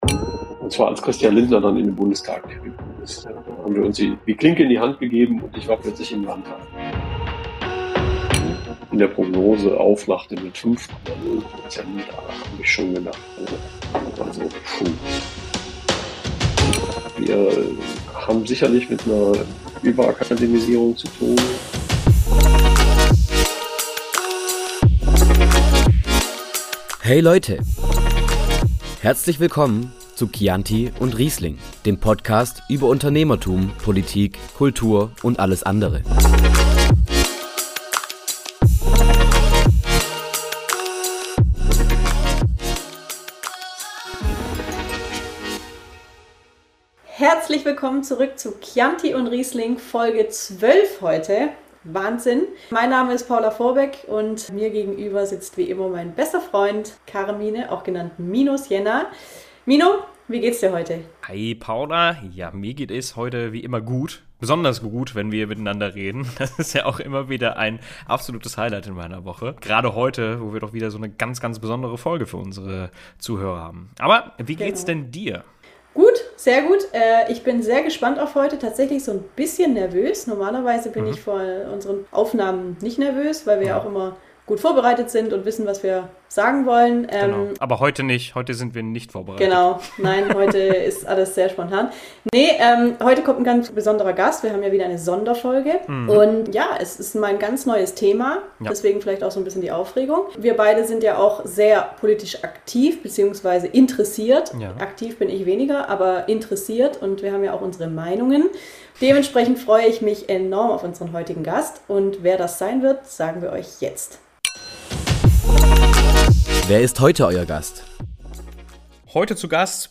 Ein spannendes Gespräch mit einem Mann, der unser Land mitgestaltet und uns hinter die Kulissen der politischen Bühne blicken lässt. Wie immer besprechen wir am Ende wieder das, was uns drei momentan persönlich umtreibt und diskutieren diesmal über Bürokratie, Fachkräftemangel und die heutige Bedeutung der Demokratie.